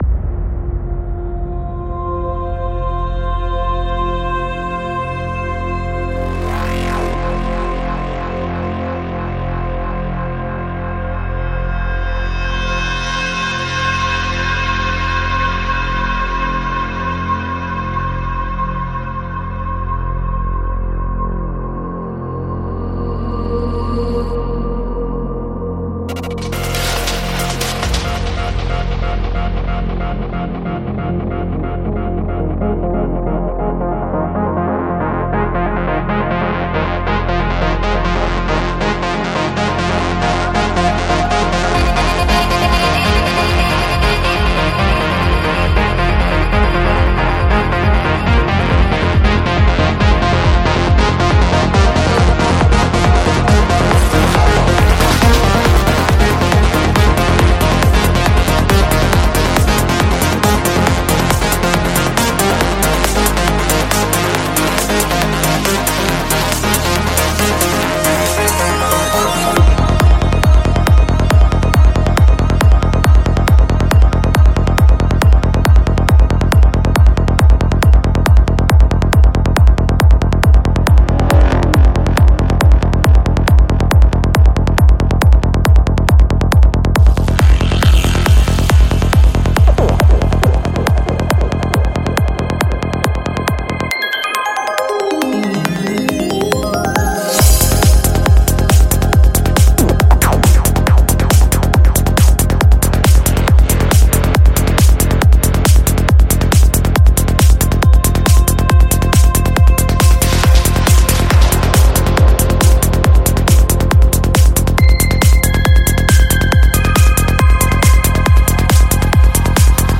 Альбом: Psy-Trance